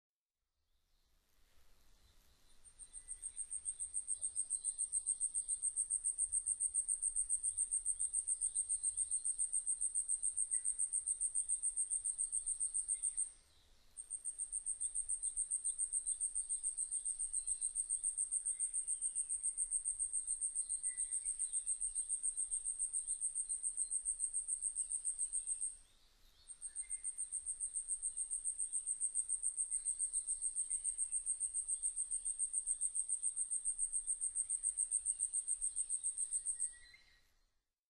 ヤブサメ　Urosphena squameicepusウグイス科
日光市稲荷川中流　alt=730m  HiFi --------------
Windows Media Audio FILE MPEG Audio Layer3 FILE  Rec.: SONY MZ-NH1
Mic.: Sound Professionals SP-TFB-2  Binaural Souce
他の自然音：　 シジュウカラ・ウグイス